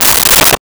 Toilet Paper Dispenser 01
Toilet Paper Dispenser 01.wav